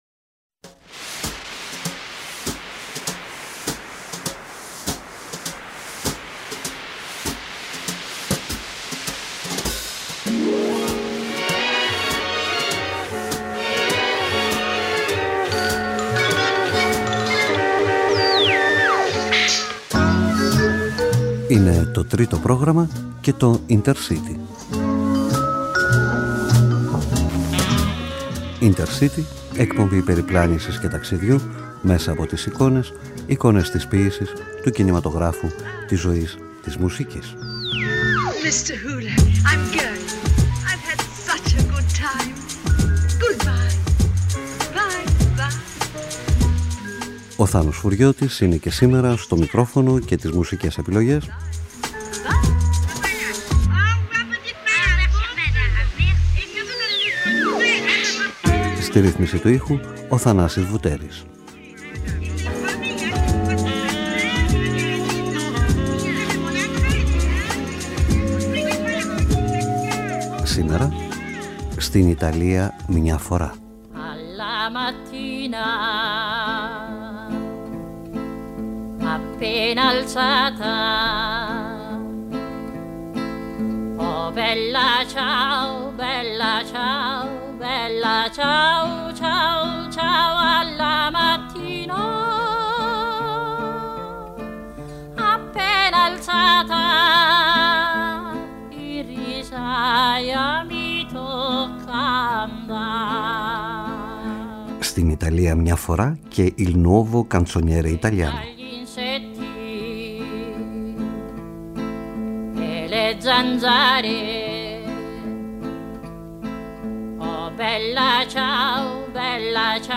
Aκούμε όλα σχεδόν τα τραγούδια της παράστασης με τις φωνές γυναικών
Εκπομπή περιπλάνησης και ταξιδιού μέσα από τις εικόνες